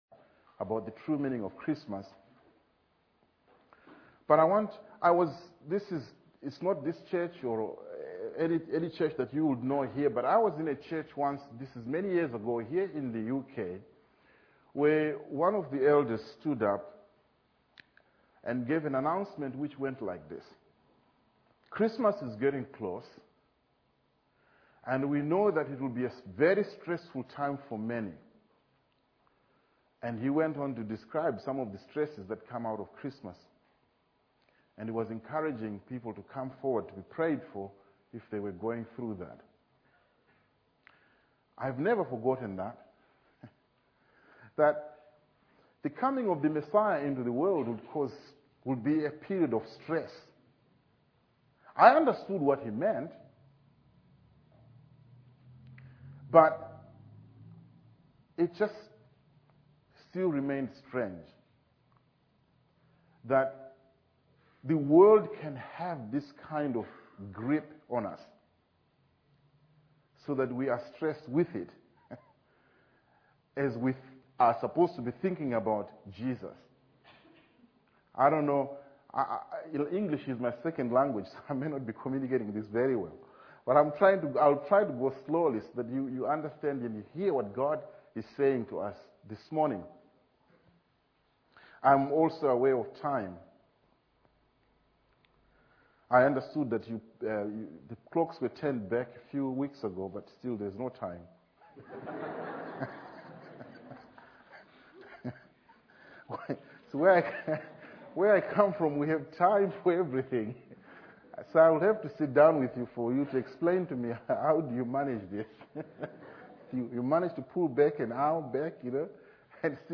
10106-sermon